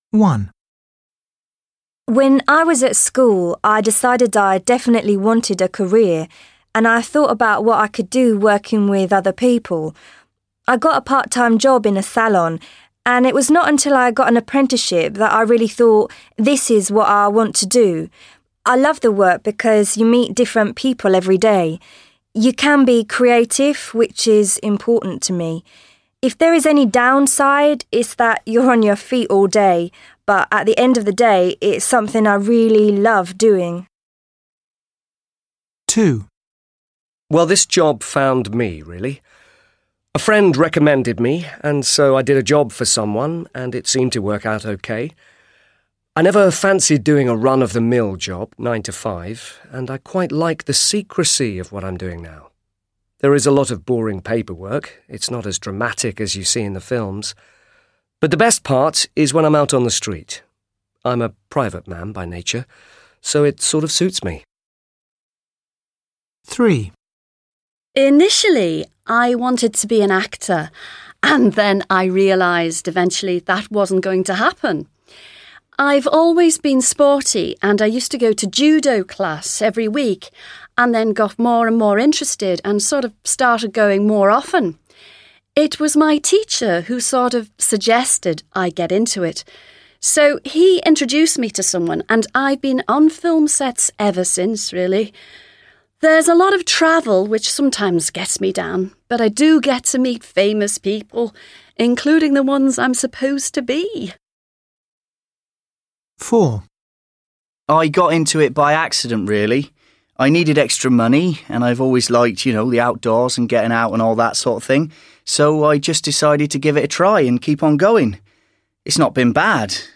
Teachers Interview Listening Audio.mp3